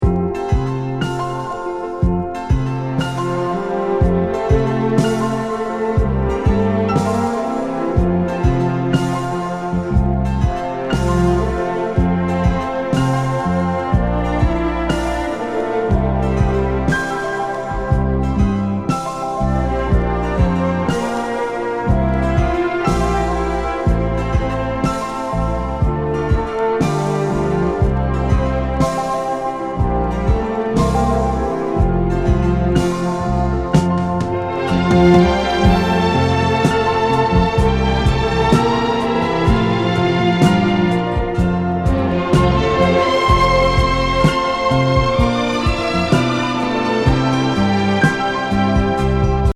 イタリアン・ポップス。